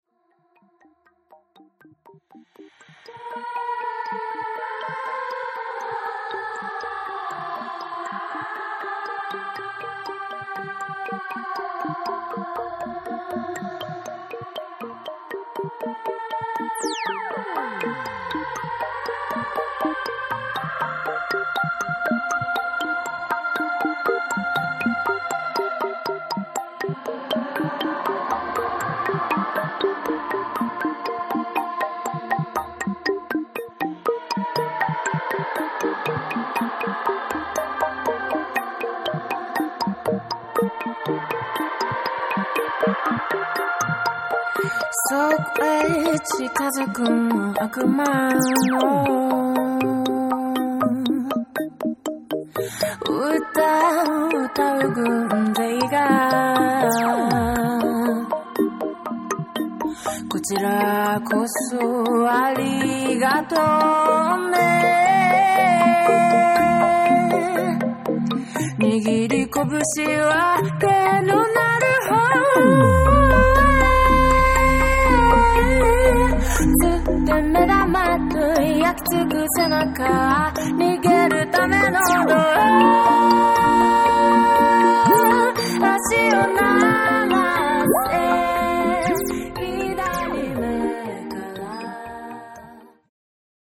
アンビエントなエレクトロニカ・サウンドが心が洗われるように気持ちいい
JAPANESE / BREAKBEATS